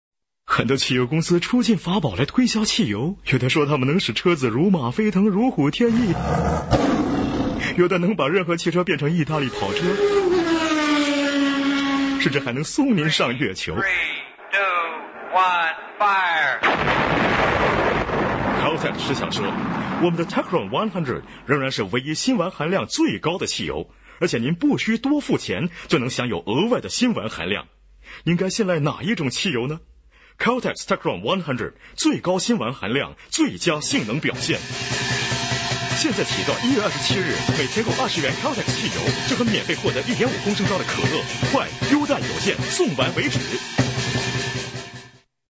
Sprecher chinesisch für Werbung, TV, Radio, Industriefilme und Podcasts.
Kein Dialekt
Professional male voice over artist from China.